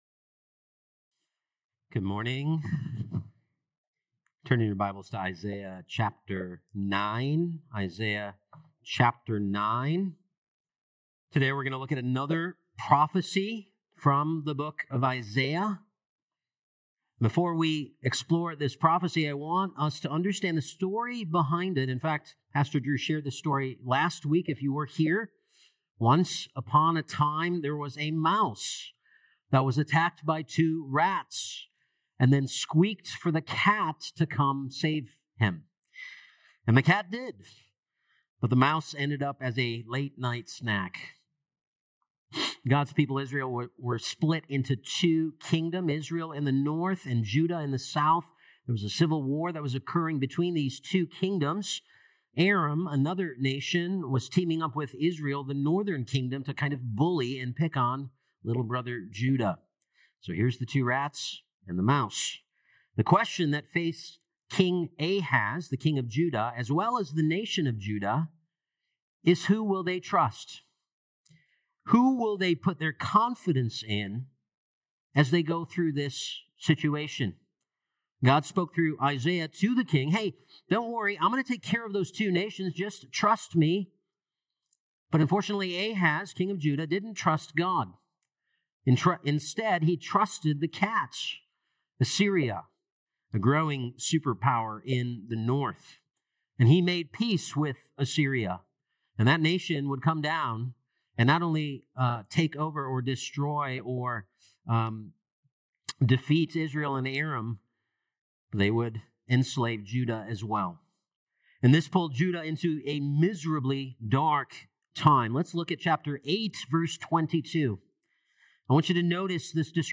Dec 8th Sermon